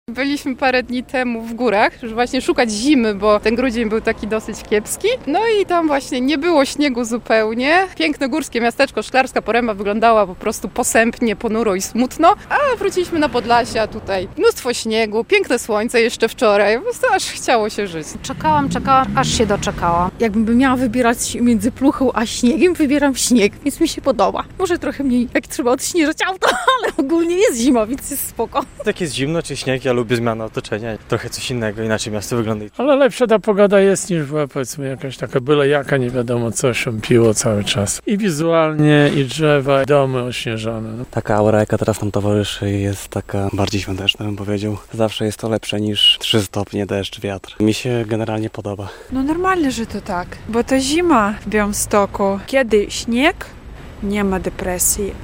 Zapytaliśmy białostoczan, co myślą o mroźnej zimie.